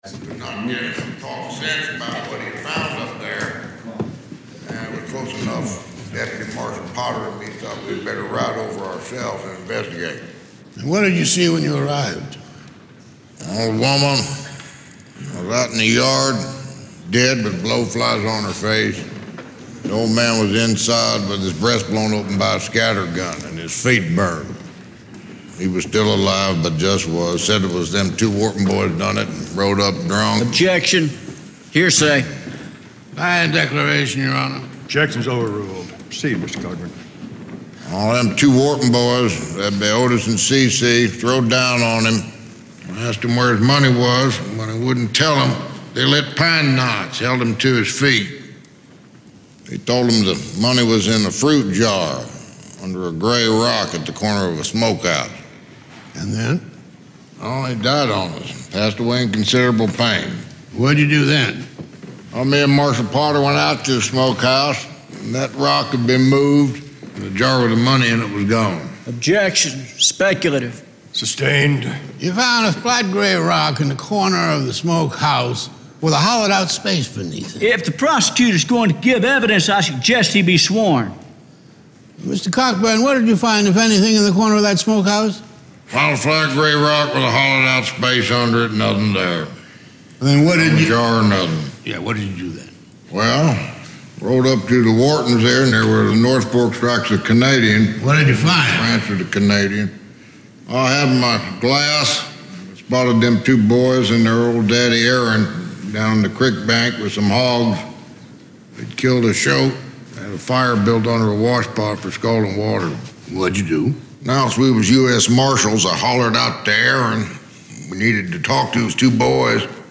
American Rhetoric: Movie Speech